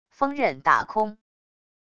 风刃打空wav音频